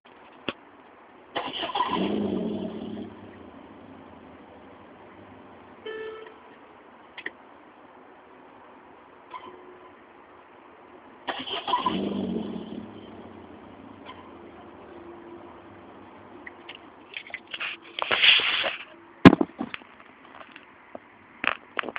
bruit